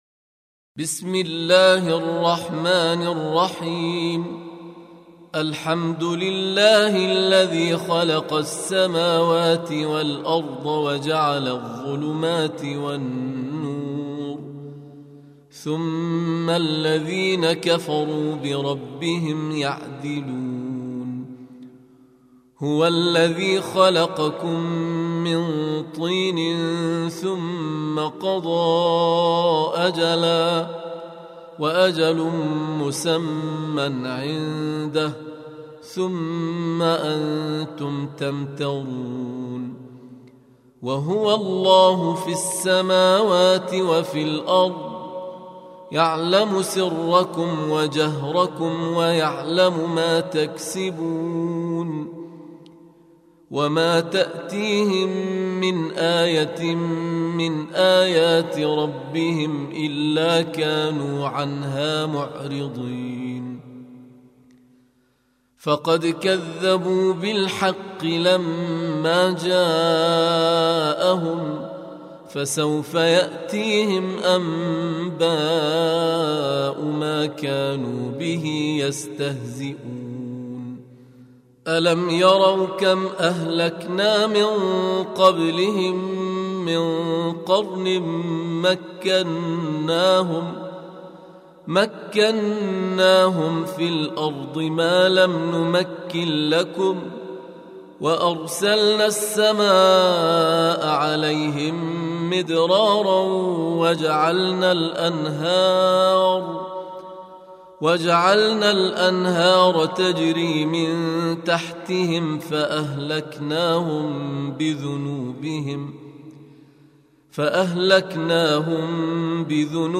Surah Sequence تتابع السورة Download Surah حمّل السورة Reciting Murattalah Audio for 6. Surah Al-An'�m سورة الأنعام N.B *Surah Includes Al-Basmalah Reciters Sequents تتابع التلاوات Reciters Repeats تكرار التلاوات